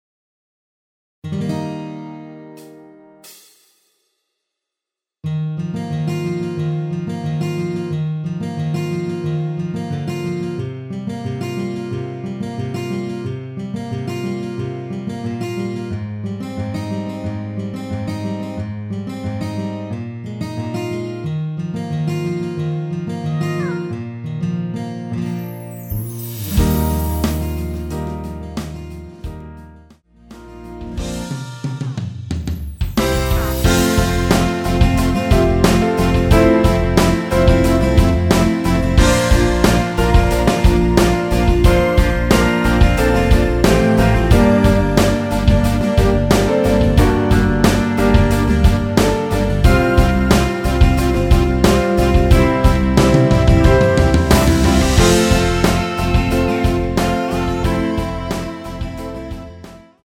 전주 없이 시작 하는곡이라 인트로 4박 만들어 놓았습니다.(미리듣기 참조)
Eb
◈ 곡명 옆 (-1)은 반음 내림, (+1)은 반음 올림 입니다.
앞부분30초, 뒷부분30초씩 편집해서 올려 드리고 있습니다.
중간에 음이 끈어지고 다시 나오는 이유는